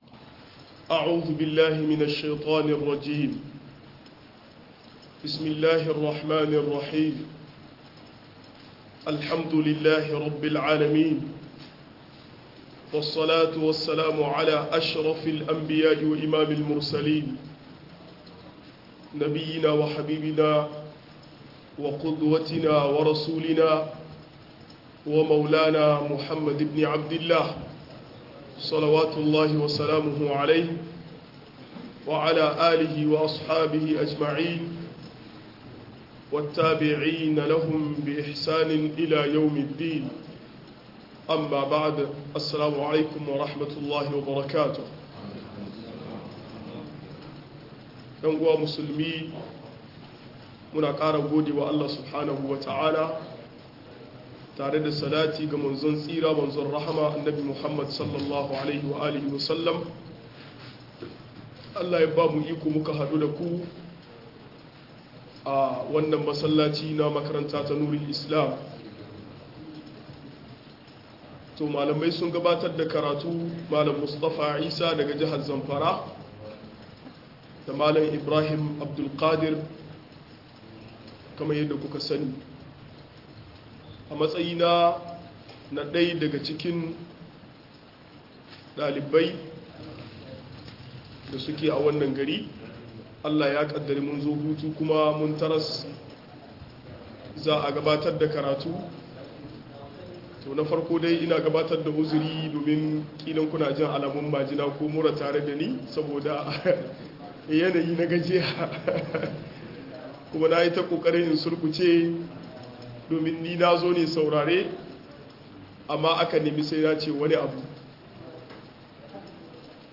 Wasu shubhohi kan aure - MUHADARA